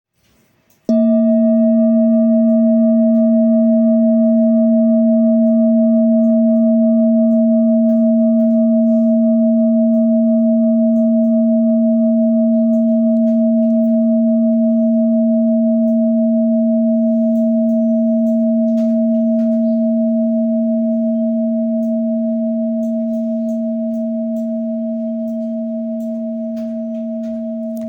Singing Bowl, Buddhist Hand Beaten, with Fine Etching Carving
Material Seven Bronze Metal